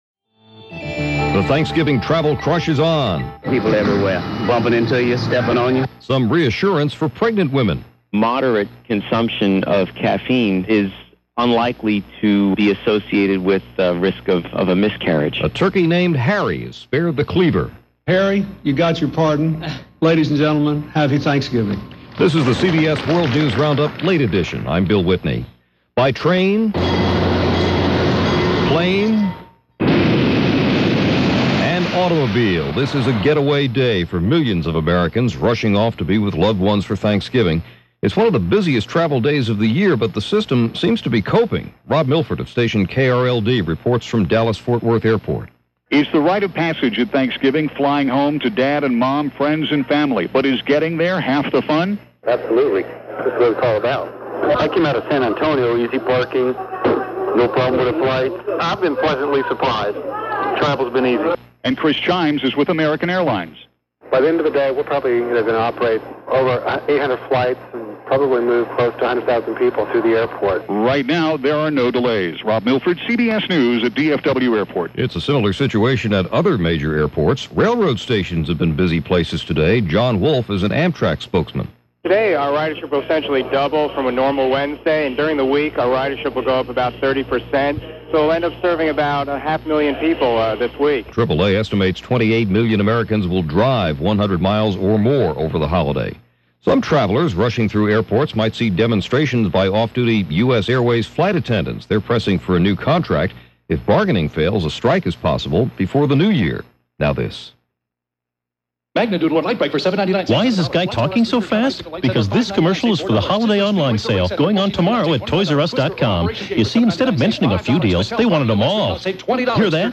That’s just a little of what went on in this world, Wednesday November 24, 1999 as presented by the CBS World News Roundup, Late Edition.